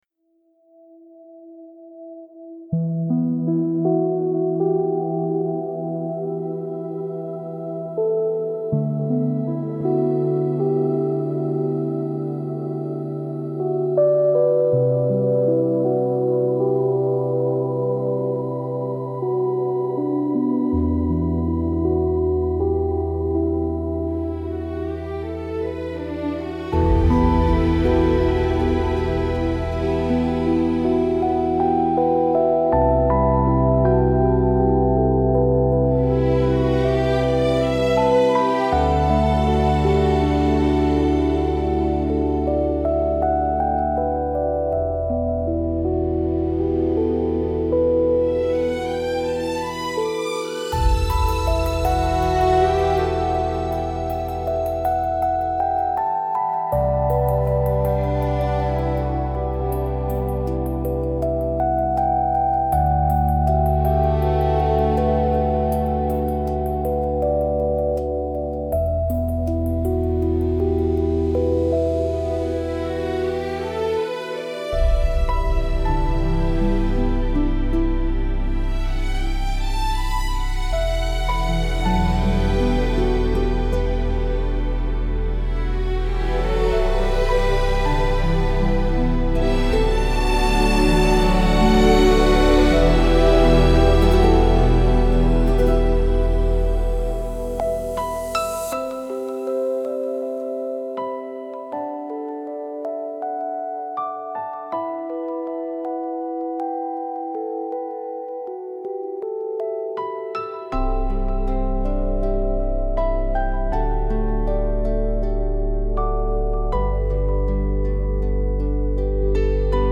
ПЕЧАЛЬНАЯ